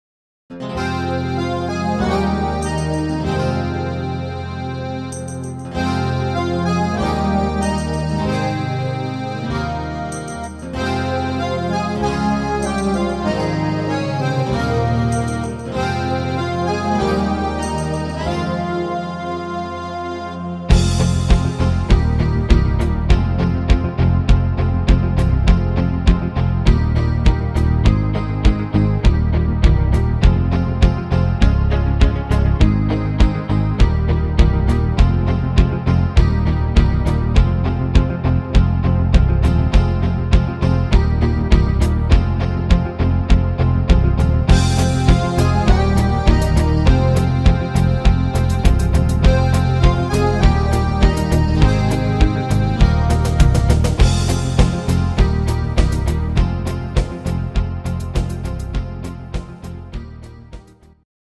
Rhythmus  8 Beat
Art  Deutsch, Duette, Weihnachtslieder